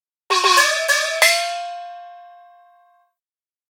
Gong.ogg